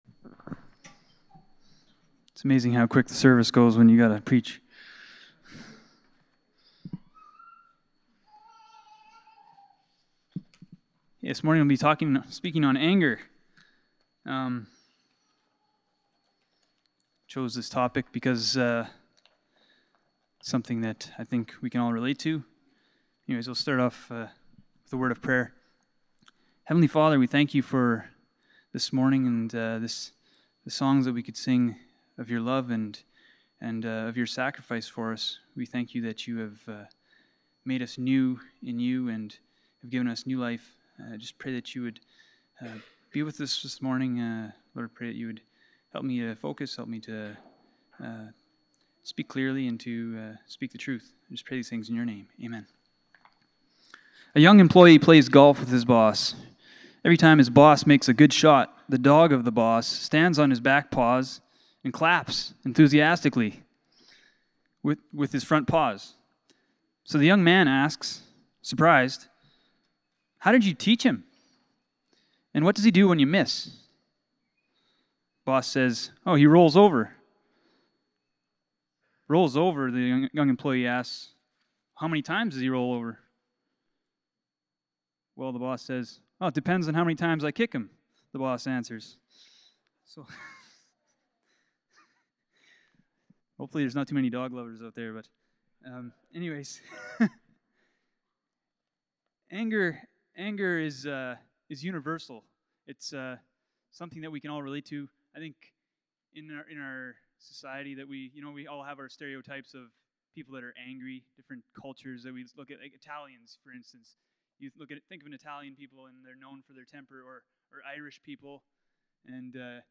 Passage: Ephesians 4:17-24 Service Type: Sunday Morning